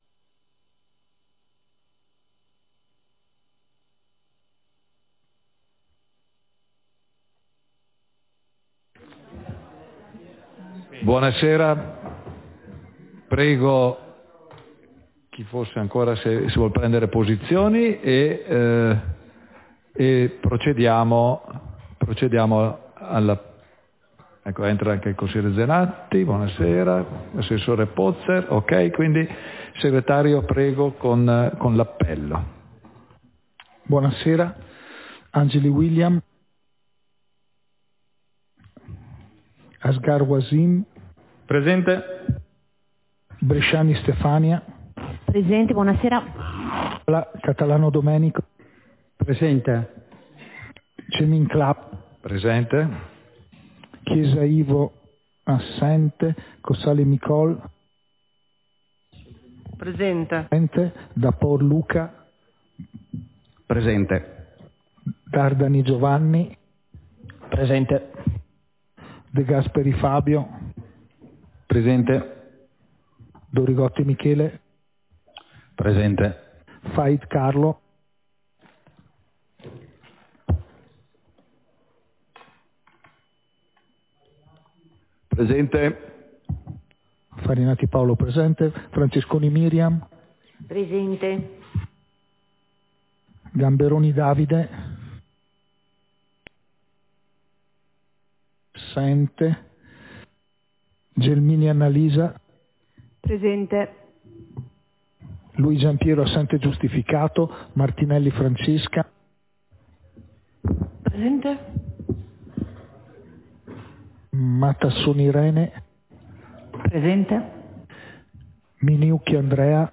Seduta del consiglio comunale - 1 ottobre 2024